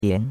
bian2.mp3